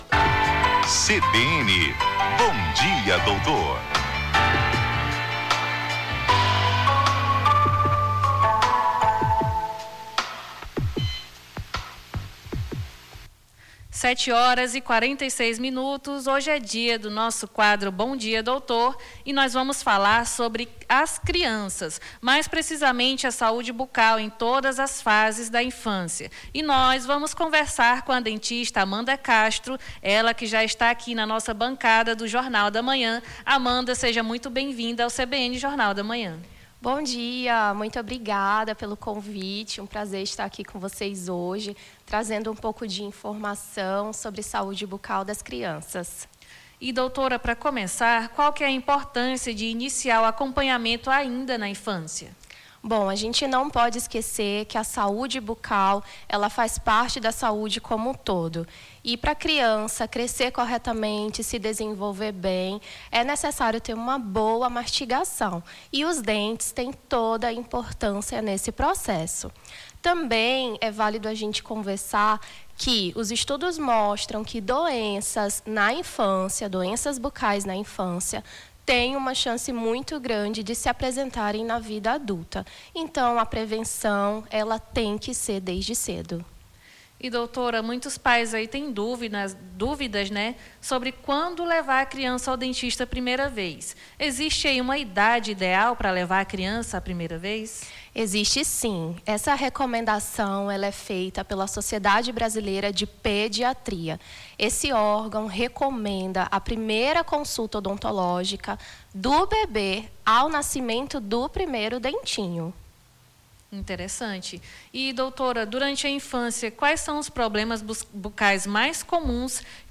Na manhã desta quinta-feira, 09, a dentista
em entrevista